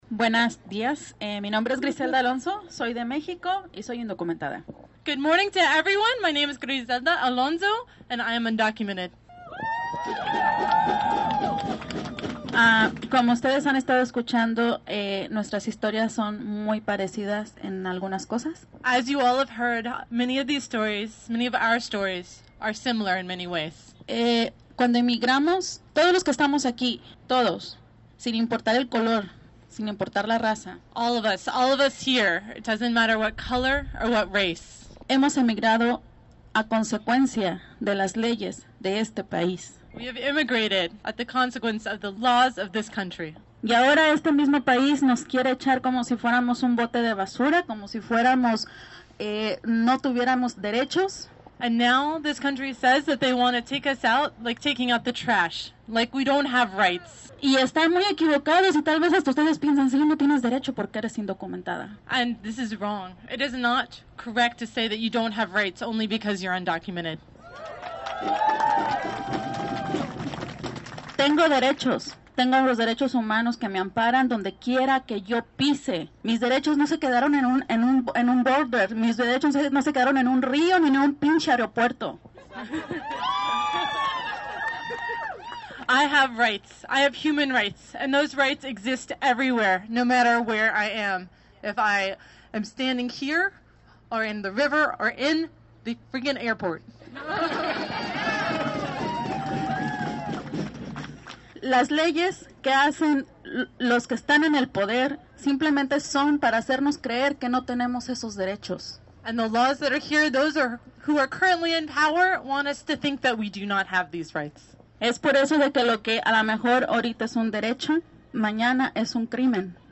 Demonstrations began locally on Friday morning, as about 150 people gathered in downtown Durham for a rally organized by Church World Service.